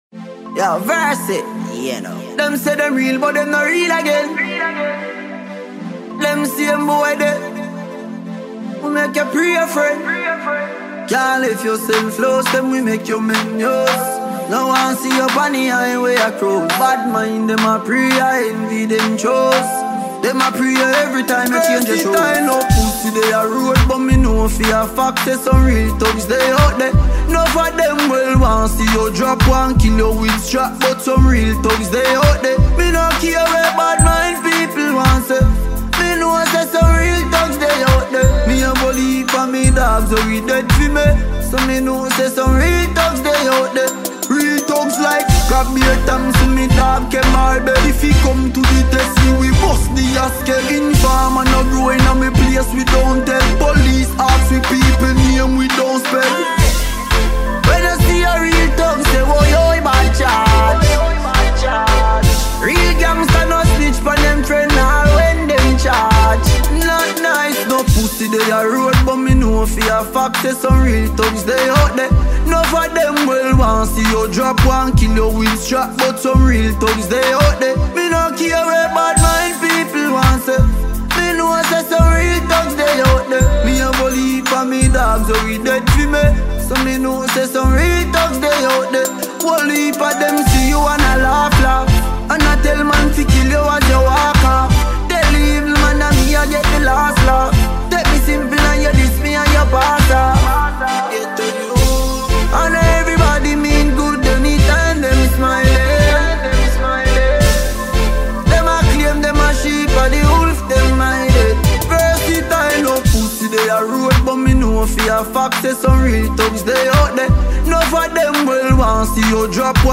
Reggae/Dancehall
New dancehall chune